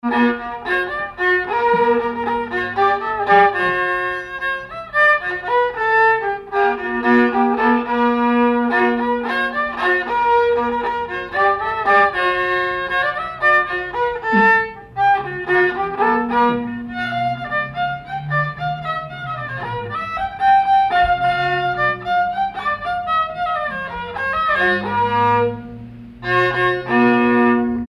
danse : polka
circonstance : bal, dancerie
Pièce musicale inédite